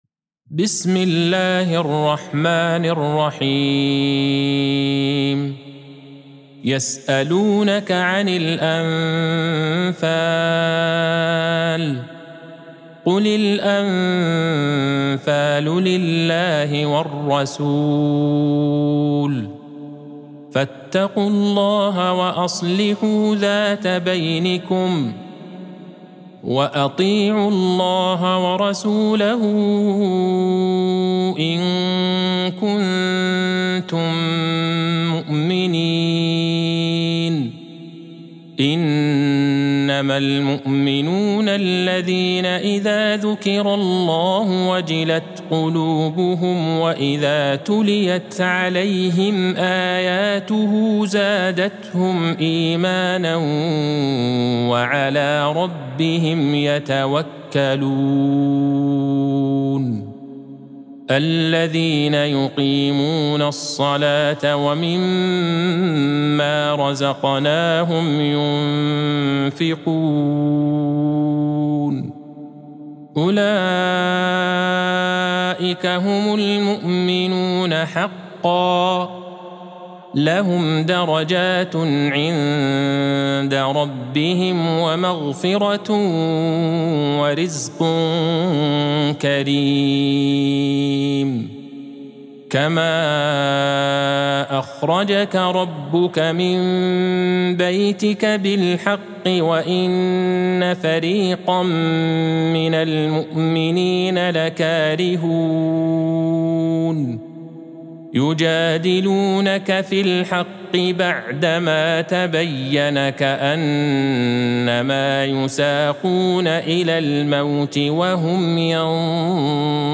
سورة الأنفال Surat Al-Anfal | مصحف المقارئ القرآنية > الختمة المرتلة ( مصحف المقارئ القرآنية) للشيخ عبدالله البعيجان > المصحف - تلاوات الحرمين